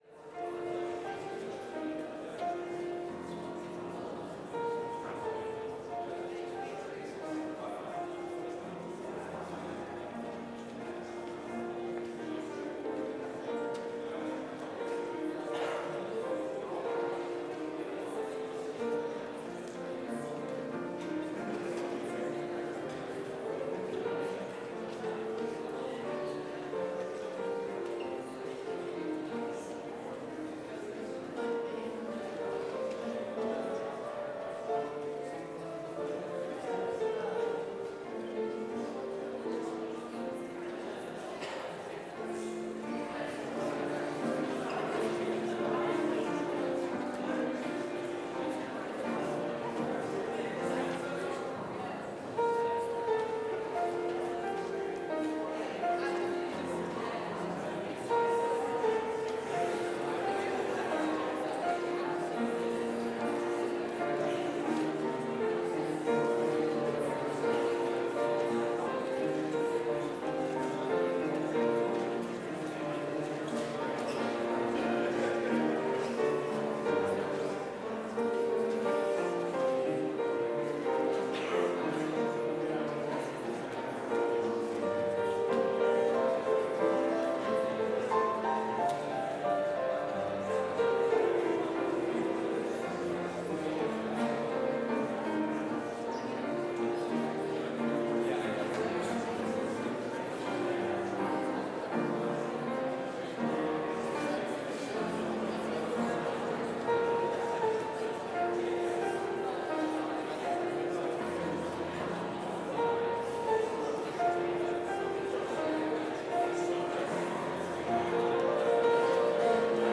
Complete service audio for Chapel - Wednesday, November 20, 2024